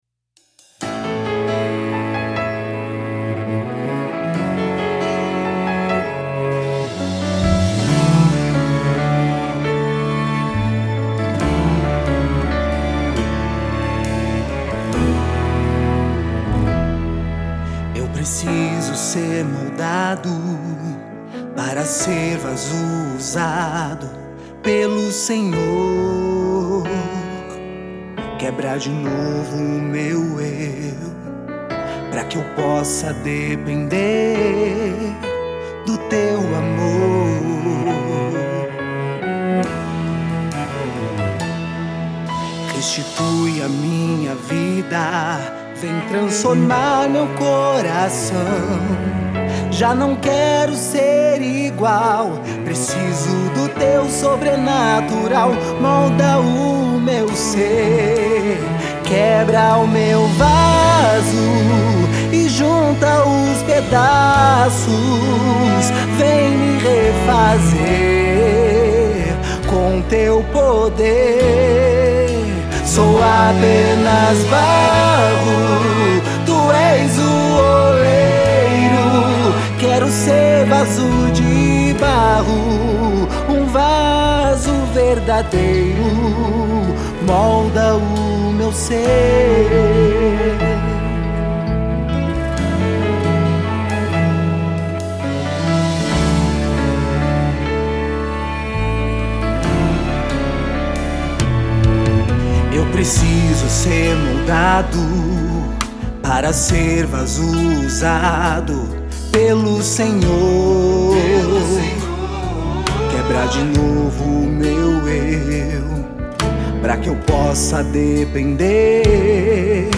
EstiloGospel